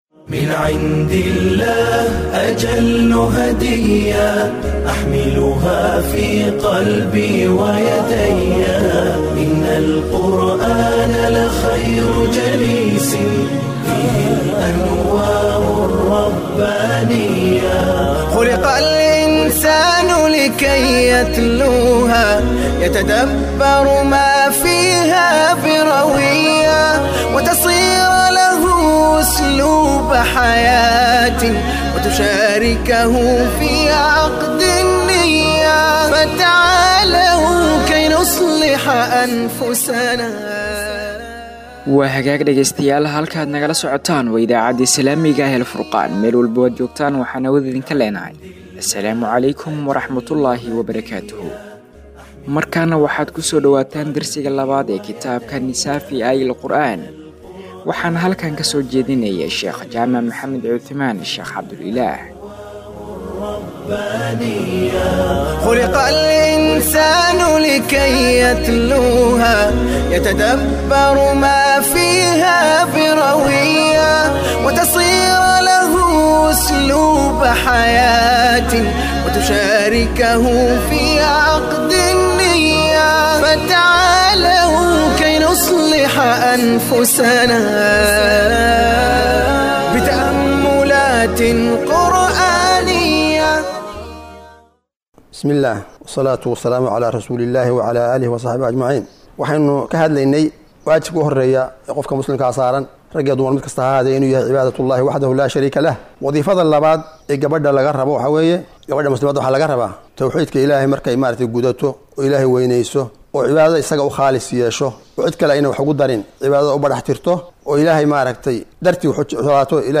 Inta lagu guda jiro bishaan waxaan dhageystayaasheenna ugu tala galnay duruus Ramadaani ah